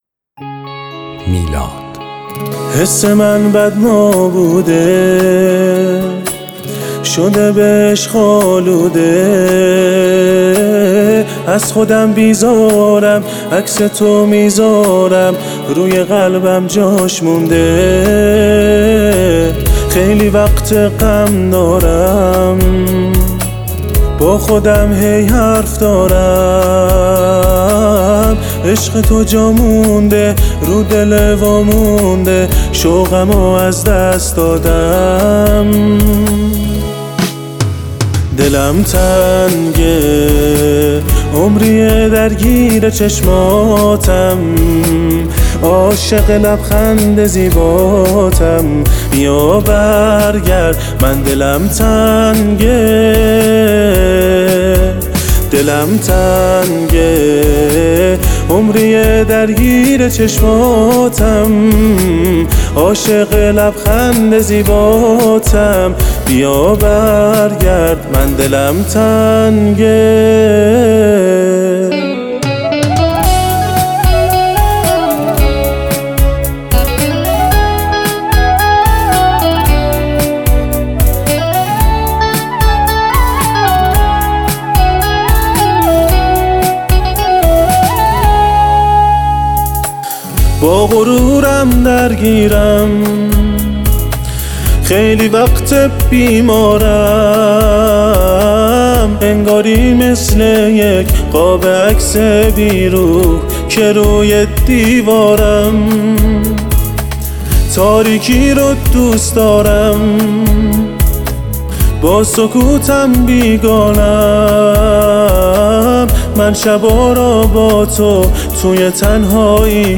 یک آهنگ احساسی و پر از دلتنگی